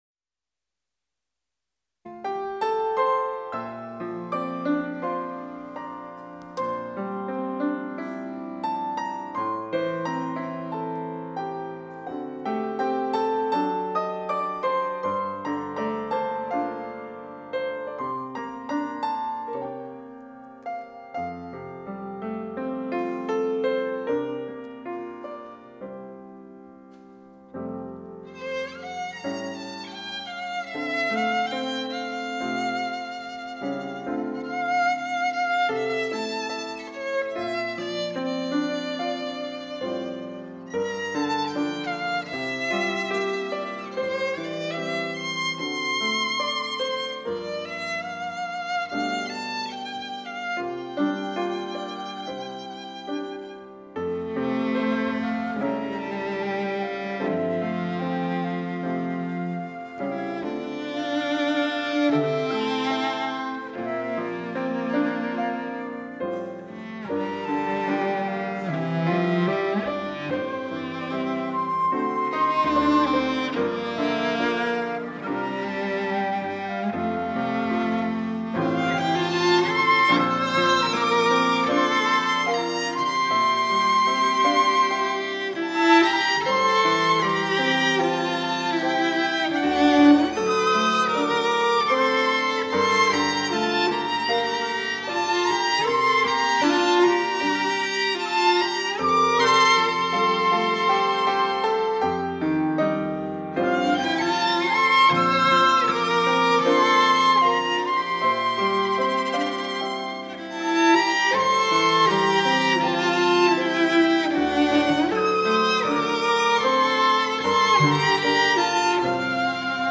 특송과 특주 - 여정
이름 샬롬 앙상블